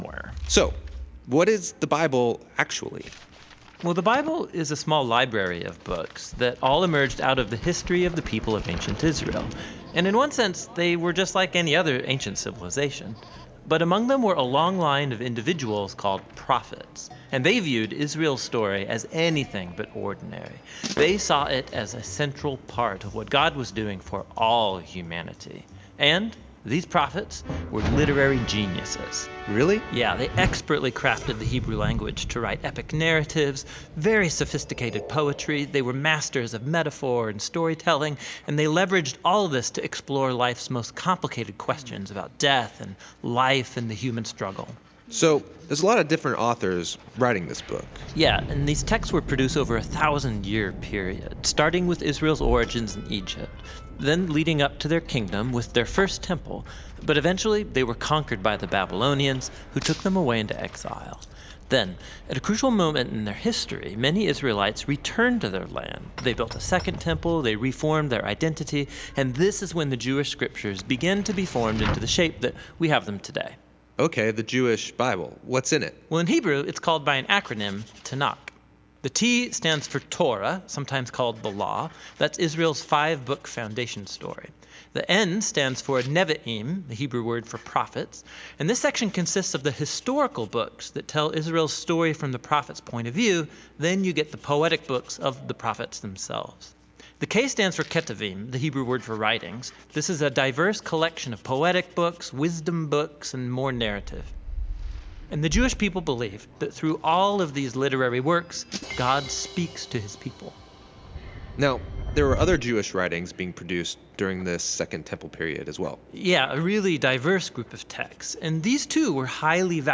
June 18 Sermon | A People For God